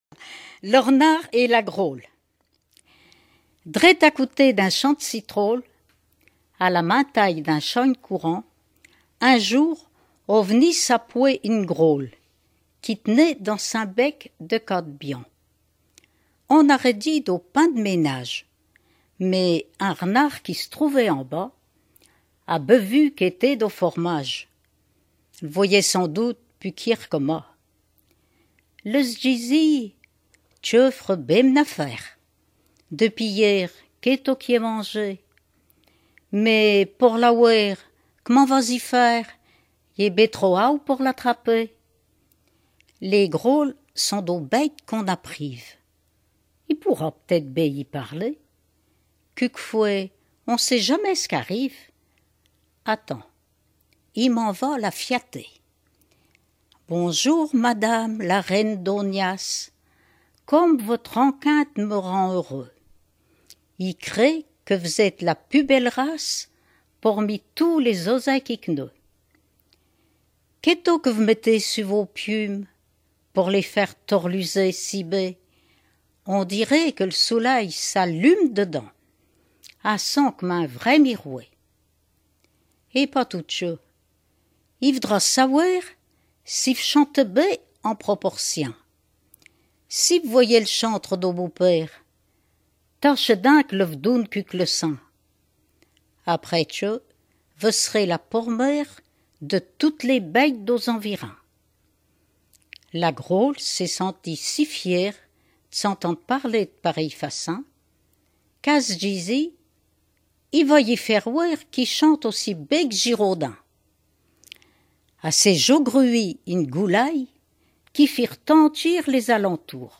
Mémoires et Patrimoines vivants - RaddO est une base de données d'archives iconographiques et sonores.
Patois local
Genre fable
Catégorie Récit